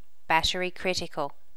Updated default sound set for notify plugin
battery critical.wav